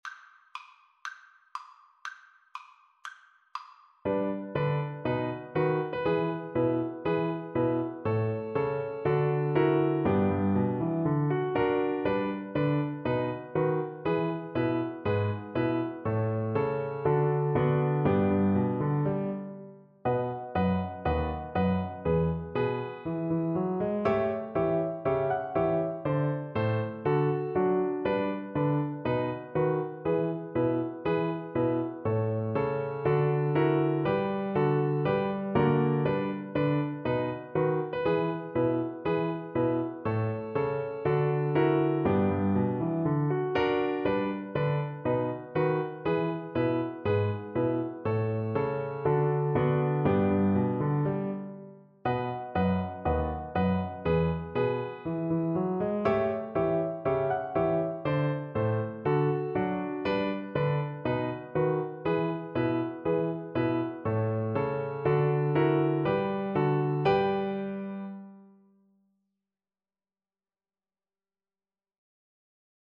Flute version
Fast! =c.120
2/2 (View more 2/2 Music)
Flute  (View more Intermediate Flute Music)
Jazz (View more Jazz Flute Music)
Rock and pop (View more Rock and pop Flute Music)